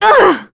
Juno-Vox_Damage_kr_02.wav